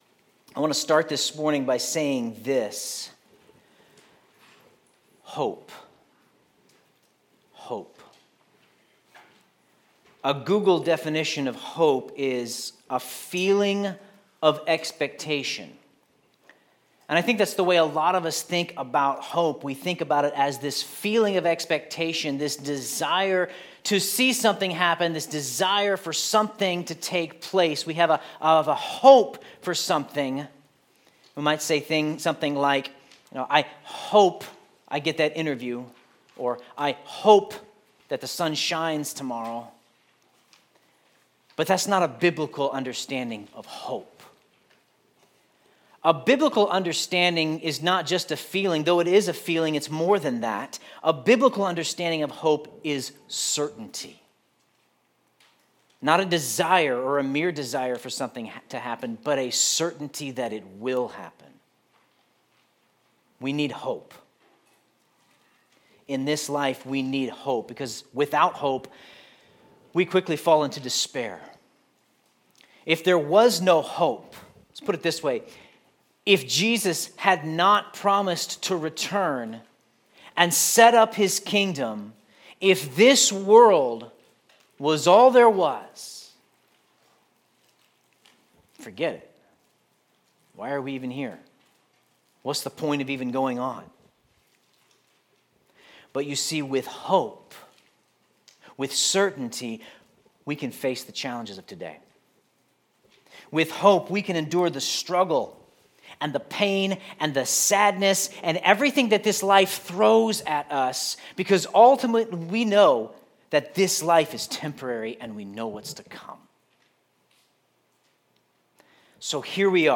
Sermon Notes A biblical understanding of hope is that it is a certainty that something will happen.